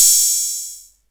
Closed Hats
DR-110Hat_O.wav